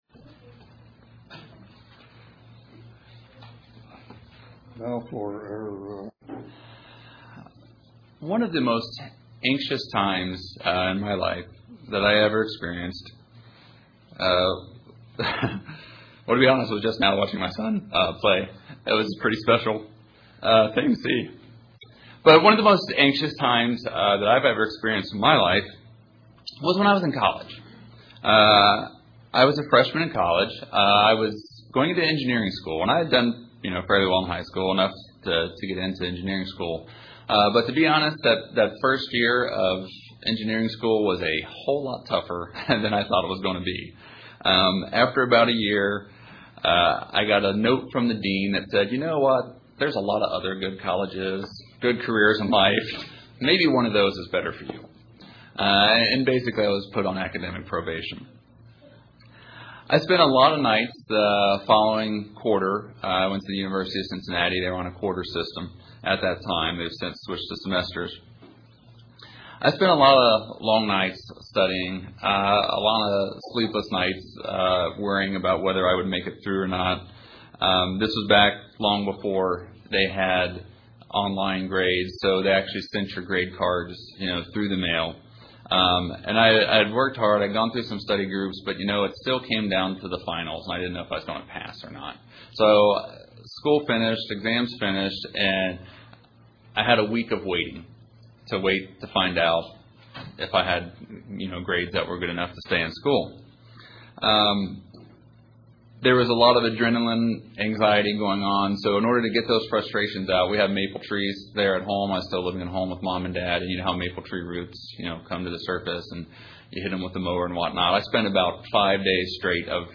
This sermon tells us who to turn to and how....
Given in Paintsville, KY